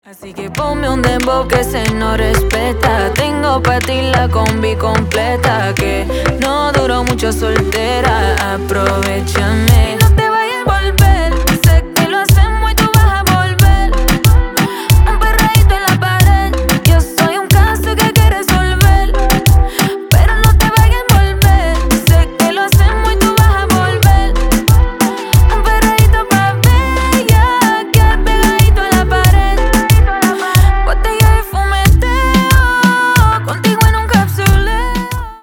Поп Музыка
латинские # клубные